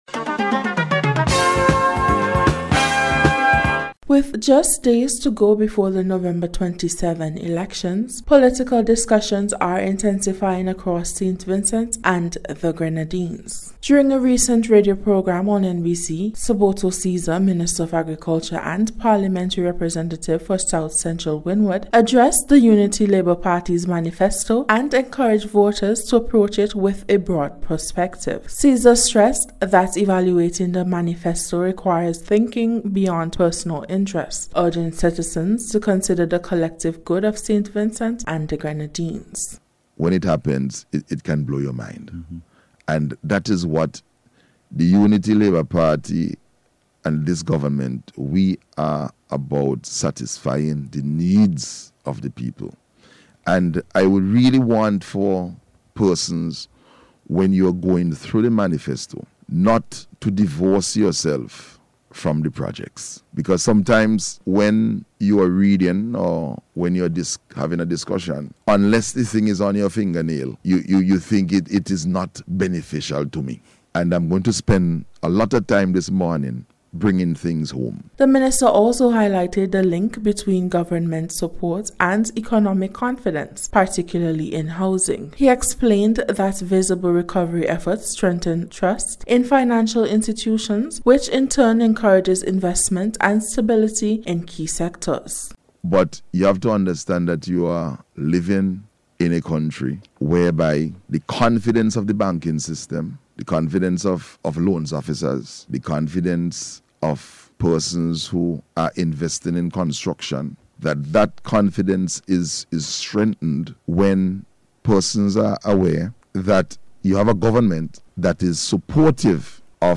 NBC’s Special Report- Friday 21st November,2025